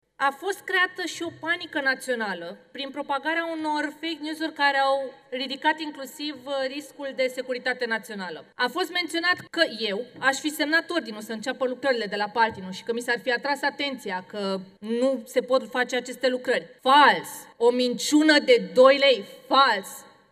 Ministra Mediului, Diana Buzoianu, a atras atenția asupra știrilor false care au circulat în timpul crizei apei din Prahova și Dâmbovița.
08dec-18-Buzoianu-despre-Fake-news.mp3